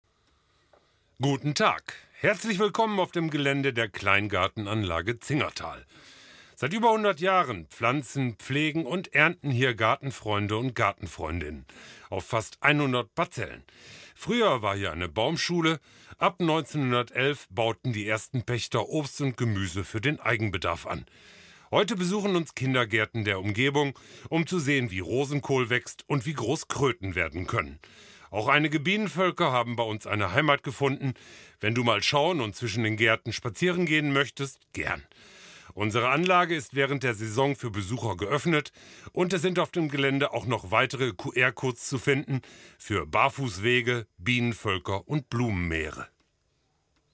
Die bisherigen Texte wurden von einem professionellen Sprecheraufgenommen, der Vereinsmitglied ist.